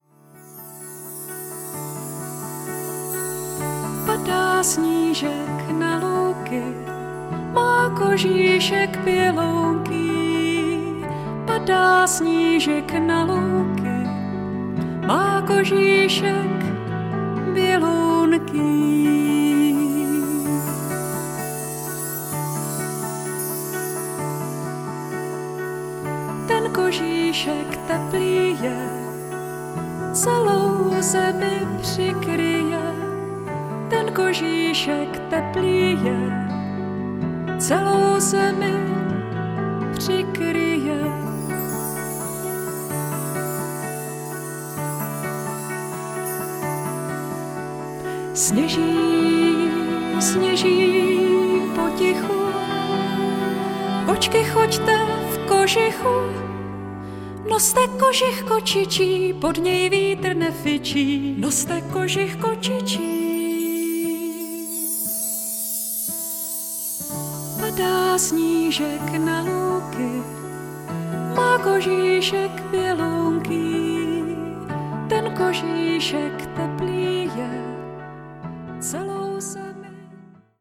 zpěv